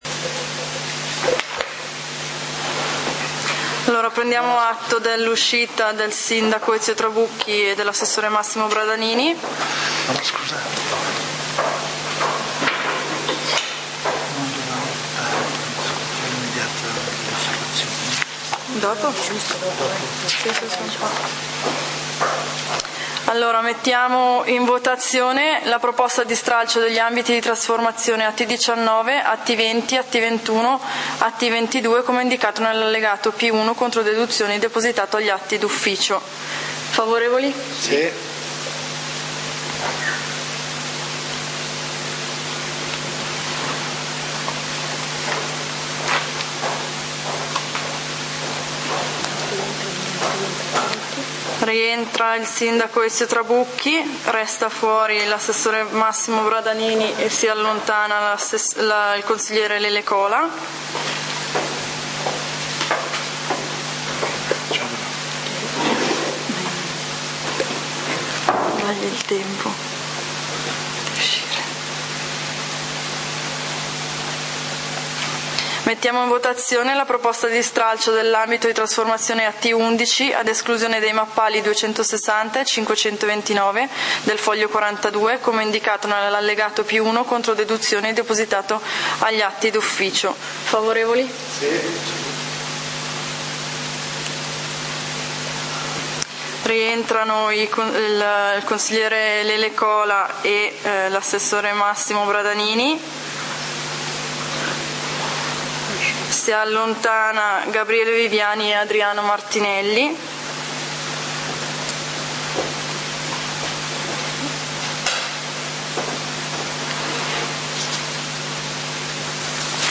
Consiglio comunale di Valdidentro del 30 Giugno 2014
Consiglio comunale del 30 Giugno 2014 torna alla lista dei punti Punto 3a: Piano di governo del territorio adottato con deliberazione di consiglio comunale n. 49 del 30.12.2013. Esame e decisione in merito alle osservazioni e ai pareri pervenuti. Approvazione definitiva; Stralcio ambiti, lettura delibera, intervento del Sindaco Ezio Trabucchi.